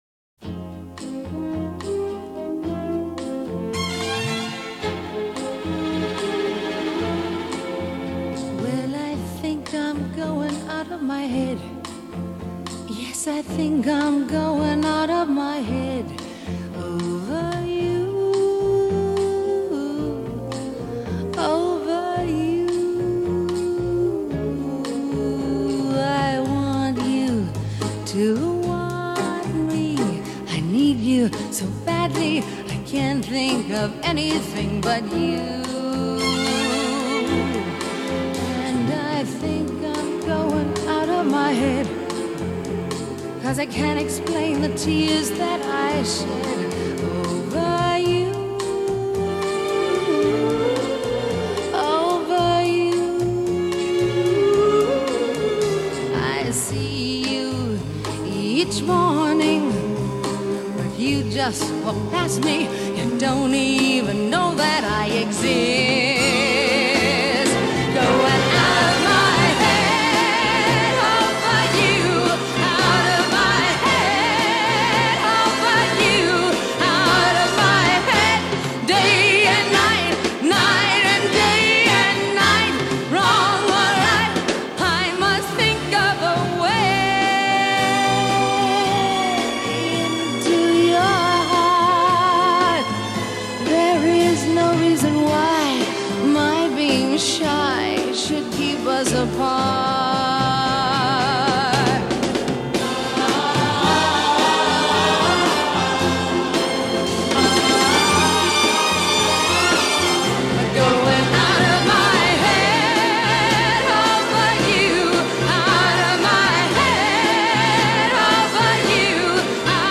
13 Over-the-Top Vocal Performances of The 1960s